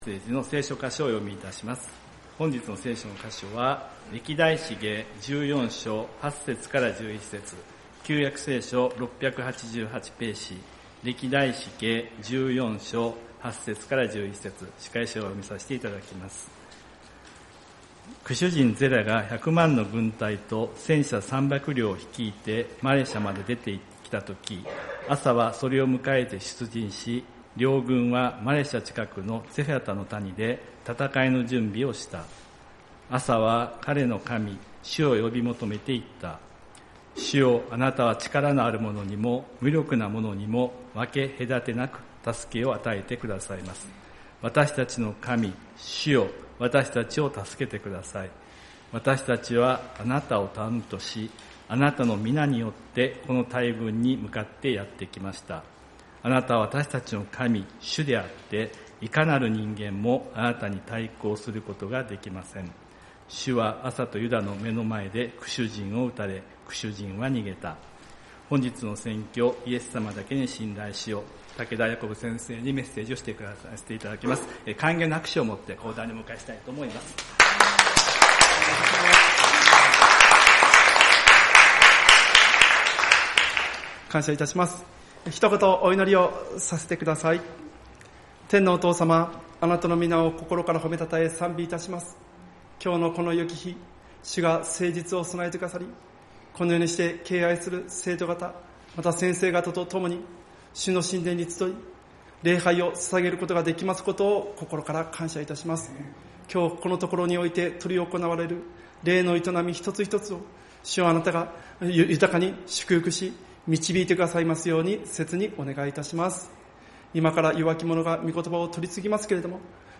2025年8月24日 聖日礼拝「イエス様だけに信頼しよう！」